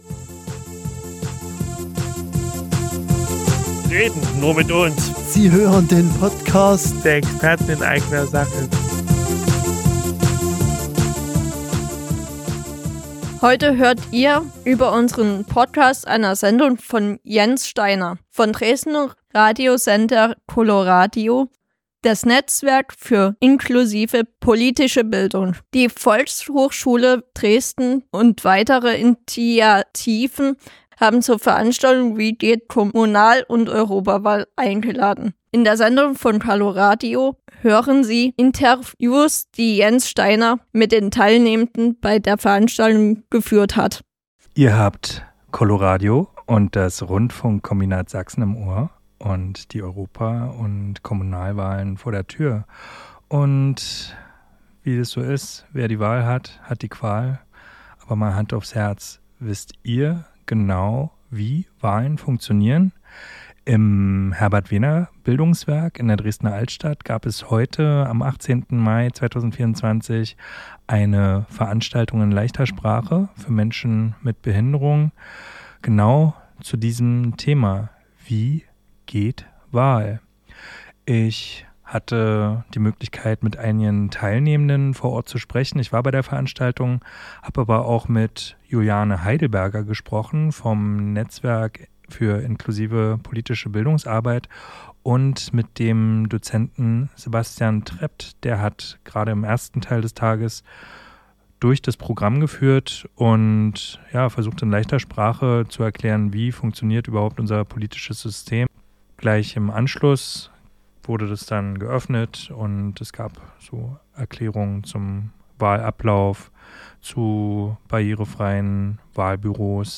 Coloradio-wie-geht-Waehlen-2024.mp3